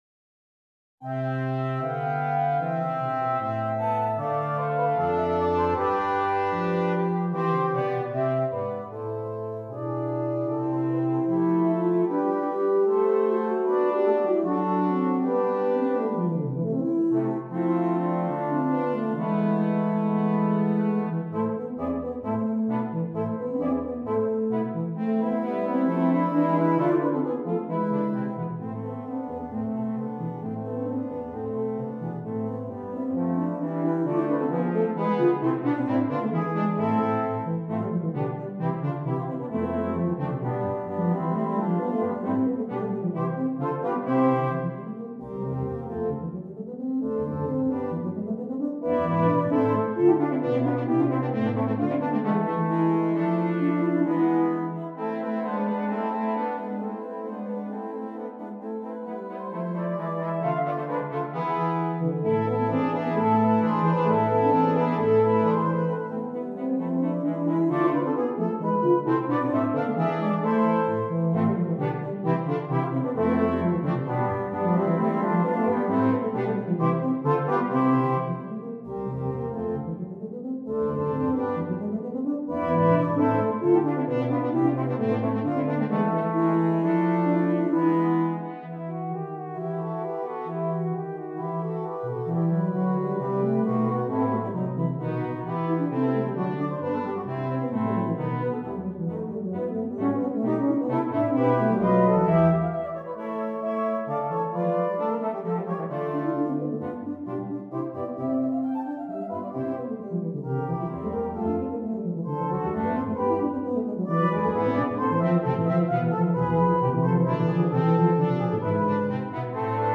Brass Quintet
Difficulty: Medium-Difficult Order Code